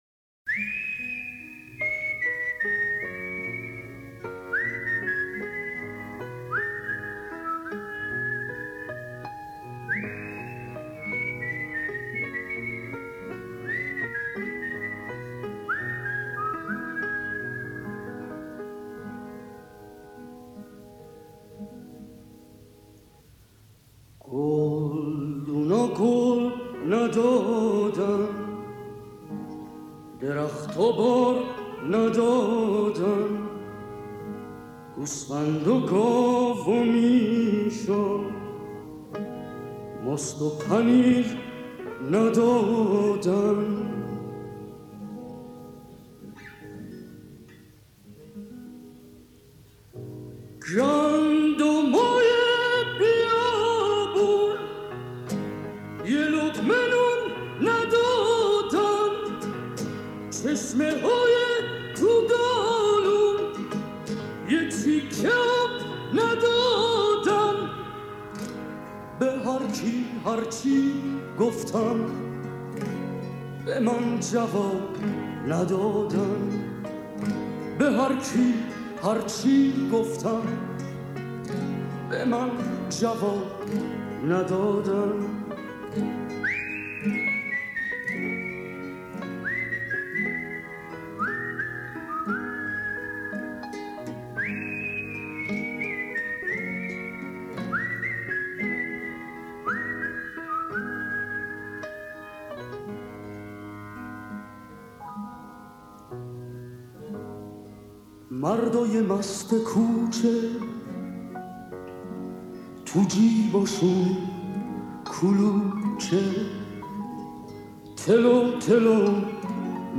توضیحات: اهنگ با سوت معروف